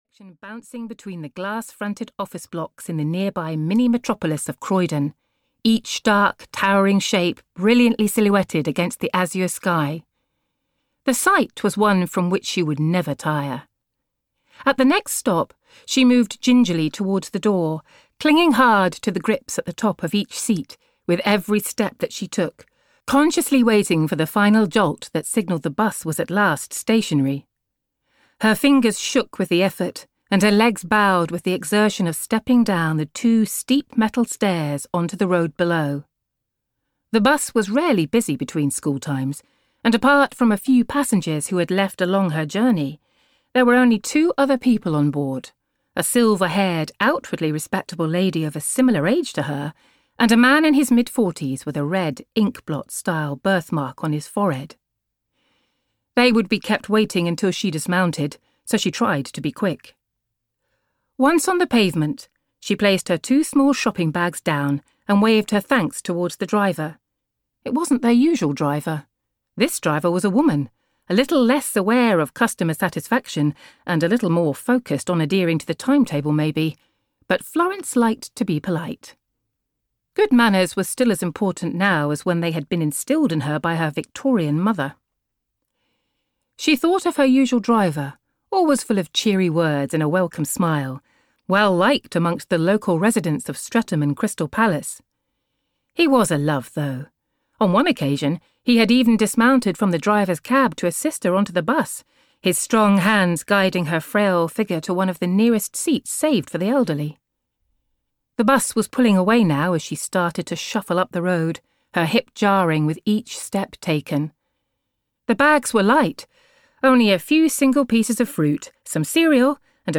Daddy's Girls (EN) audiokniha
Ukázka z knihy